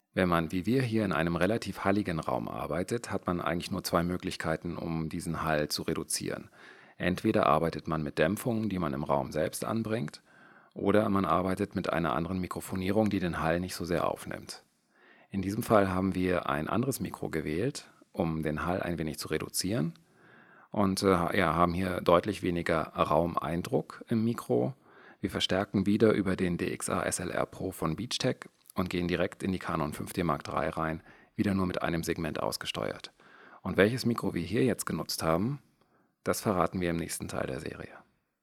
Dass eher hallige Räume auch ganz anders klingen können, zeigt folgende Aufnahme im selben Büroraum, der gleich auch einen Ausblick auf kommende Kapitel gibt. Bei dieser Aufnahmen - ebenfalls mit der Canon 5D Mark III - haben wir einen anderen Mikrofon-Typ genutzt, den wir im nächsten Teil der Serie mit vorstellen werden:
Anti_Hall_1RauschNorm.aif